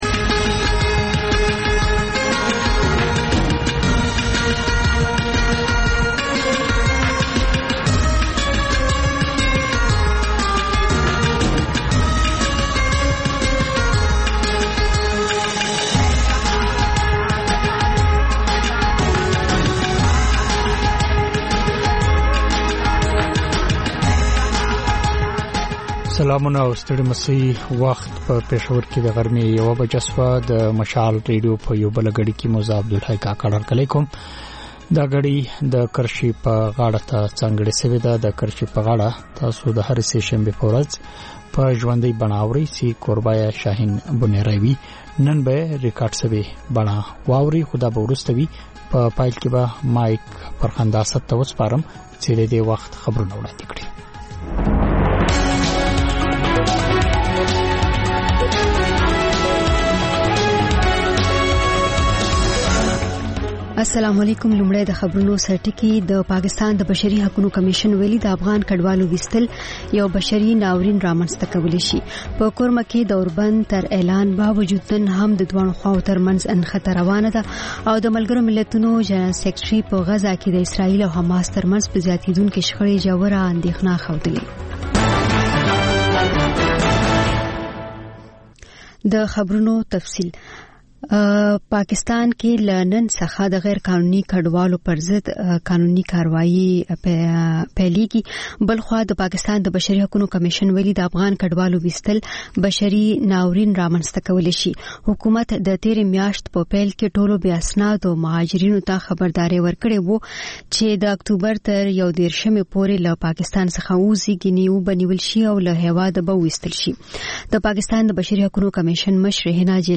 په دې خپرونه کې تر خبرونو وروسته بېلا بېل رپورټونه، شننې، مرکې خپرېږي.